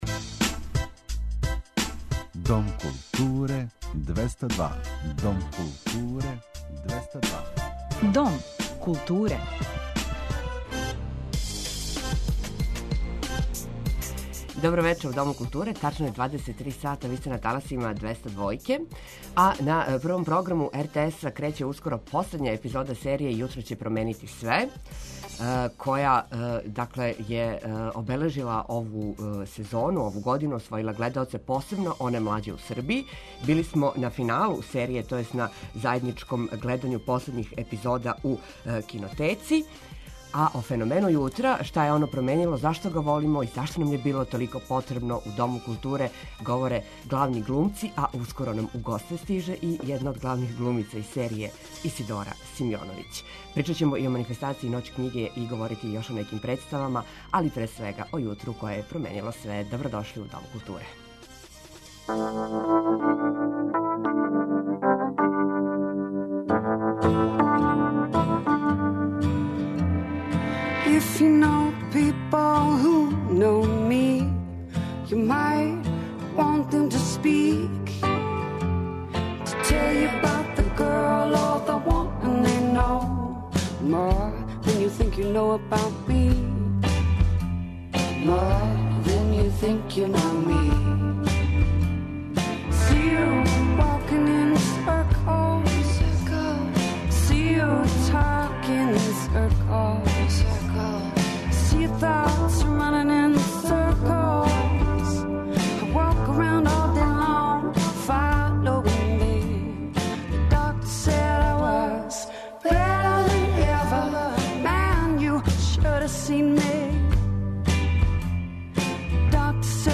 Били смо на финалу серије, то јест заједничком гледању последњих епизода у Кинотеци.